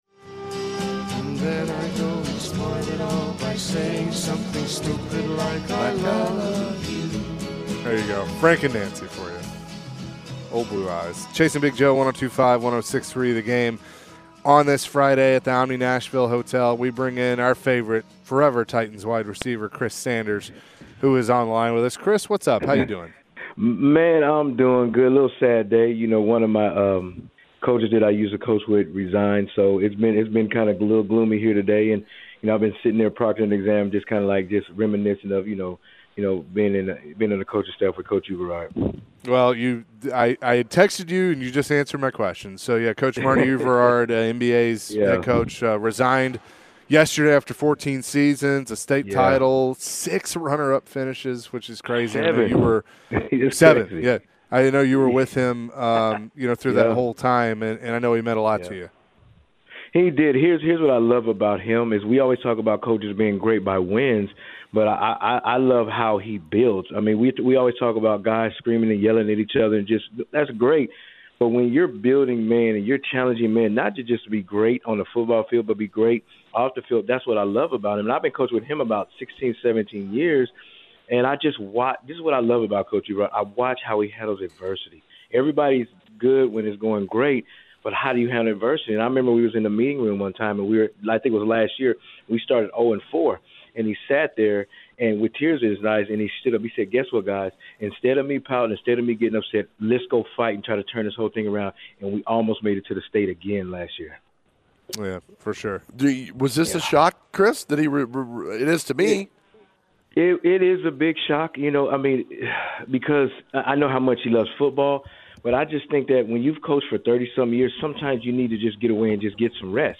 Forever Titans WR Chris Sanders joined the show and shared his thoughts on the Titans and Amy Adams not being available for media.